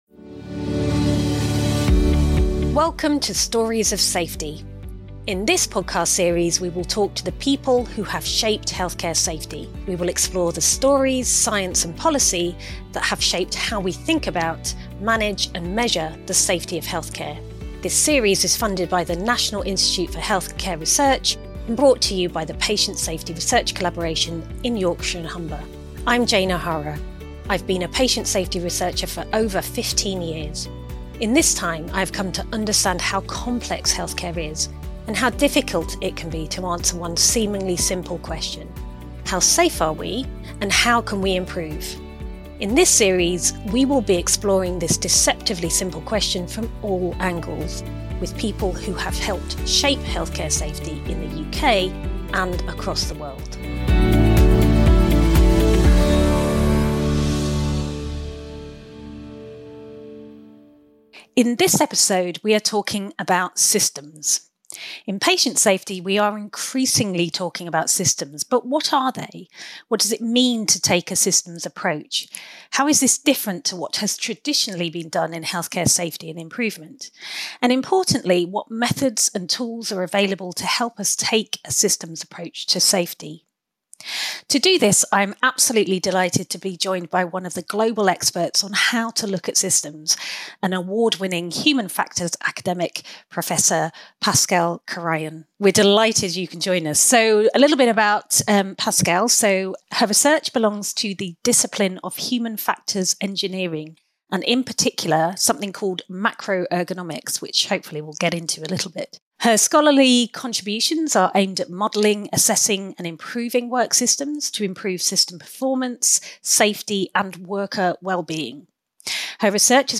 We explore how engineering, human factors/ergonomics, and organisational design can be harnessed to prevent harm, redesign complex care systems, and foster safer, more resilient healthcare environments. This episode invites listeners to consider how thoughtful system design can reduce errors and enhance safety. A deep insightful conversation with one of the field’s most respected architects of health care systems engineering.